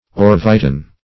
Search Result for " orvietan" : The Collaborative International Dictionary of English v.0.48: Orvietan \Or`vi*e"tan\, n. [F. orvi['e]tan: cf. It. orvietano.
orvietan.mp3